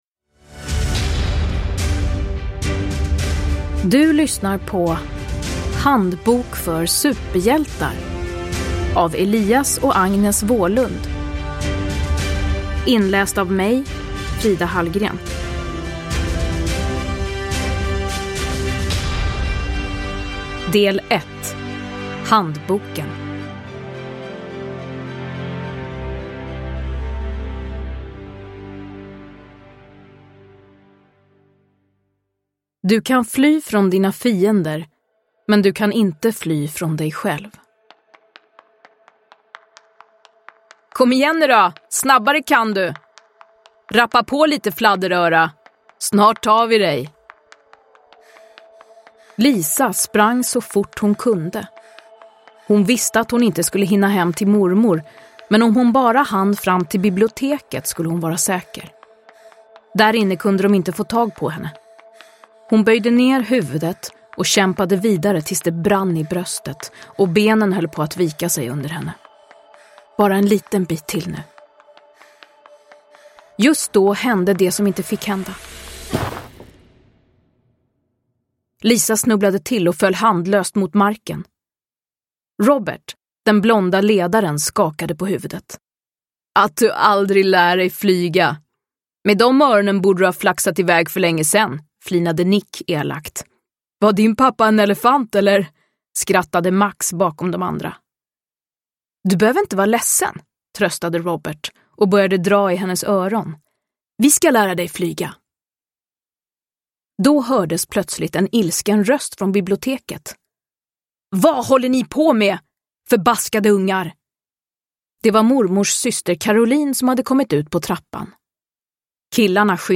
Handbok för superhjältar. Handboken – Ljudbok
Uppläsare: Frida Hallgren